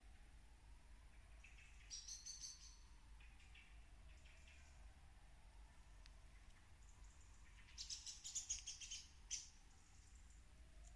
Melnais mežastrazds, Turdus merula
Administratīvā teritorijaSiguldas novads
StatussDzirdēta balss, saucieni